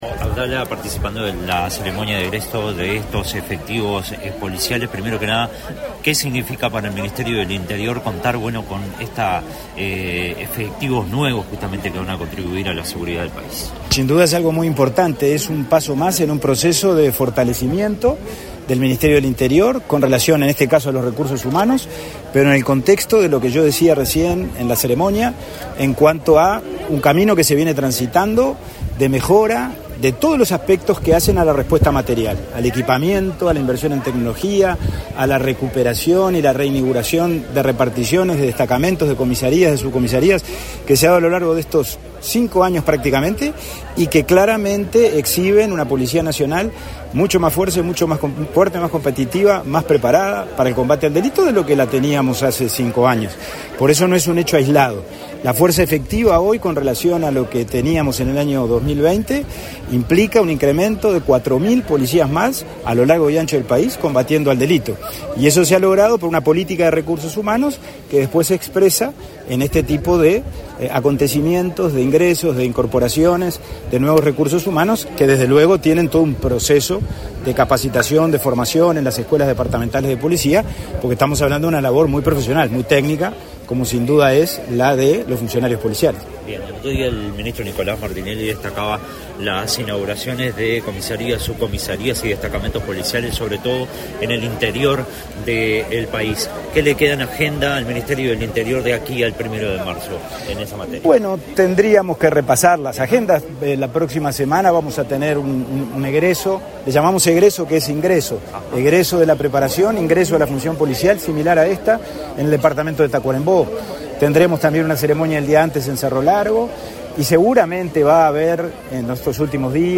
Declaraciones a la prensa del ministro interino del Interior, Pablo Abdala
Declaraciones a la prensa del ministro interino del Interior, Pablo Abdala 23/01/2025 Compartir Facebook X Copiar enlace WhatsApp LinkedIn Tras participar, este 23 de enero, en la ceremonia de egreso de 46 alumnos de la V Promoción Bicentenario de la Declaratoria de la Independencia, que se desempeñarán como agentes en la Jefatura de Policía de San José, el ministro interino del Interior, Pablo Abdala, realizó declaraciones a la prensa.